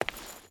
Stone Chain Walk 5.ogg